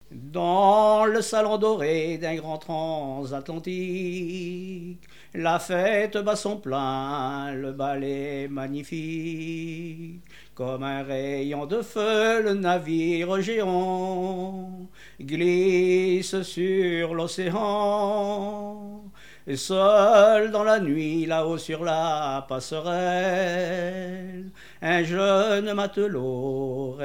chansons maritimes contemporaines
Pièce musicale inédite